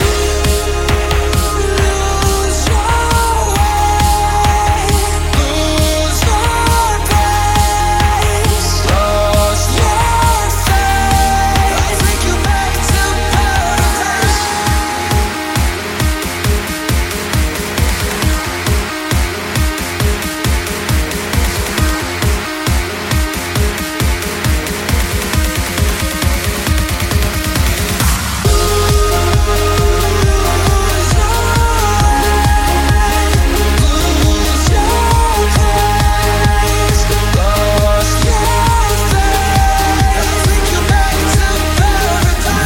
Genere: dance, edm, club, remix